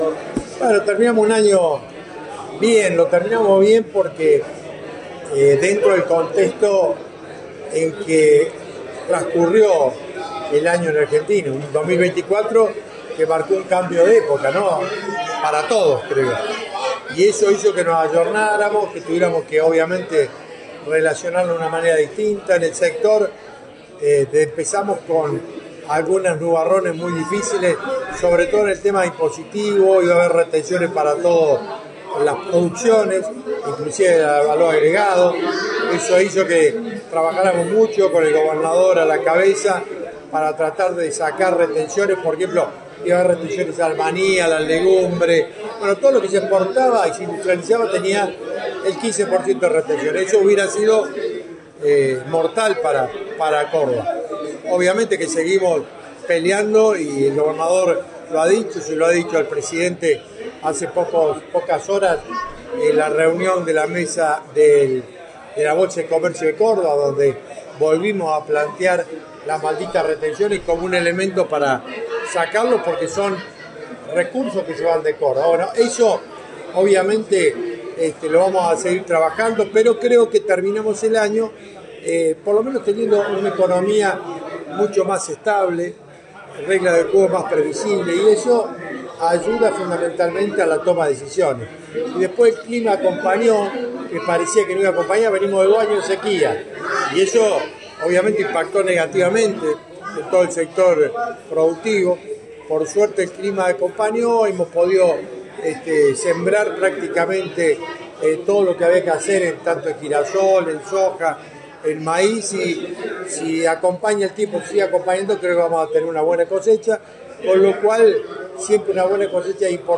El ministro de Bioagroindustria, Sergio Busso, estuvo en Villa María durante el brindis que se desarrolló en la municipalidad junto a periodistas y dialogó sobre un tema que ya había vuelto a poner en agenda el ministro López días atrás: las retenciones.